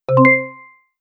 unlocked.wav